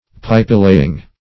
Meaning of pipelaying. pipelaying synonyms, pronunciation, spelling and more from Free Dictionary.
Search Result for " pipelaying" : The Collaborative International Dictionary of English v.0.48: Pipelaying \Pipe"lay`ing\, n., or Pipe laying \Pipe" lay`ing\ 1.